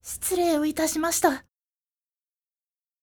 ボイス
ダウンロード 女性_「失礼をいたしました。」
女性返事